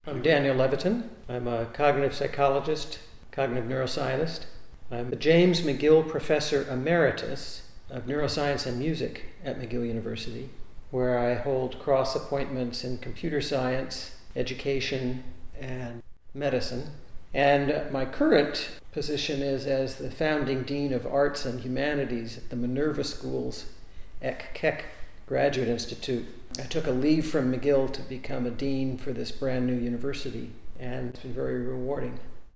Dr. Levitin introduces himself in this first excerpt: